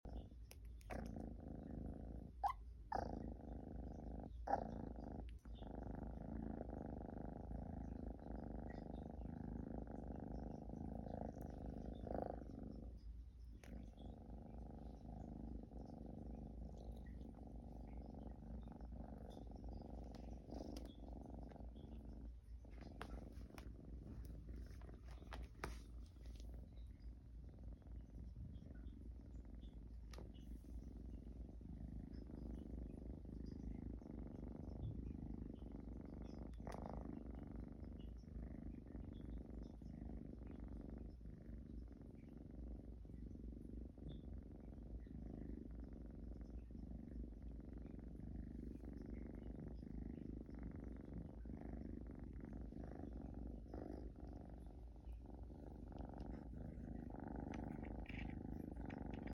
Please enjoy cat purring moments sound effects free download
Please enjoy cat purring moments for relaxation